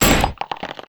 SPLAT_Generic_23_mono.wav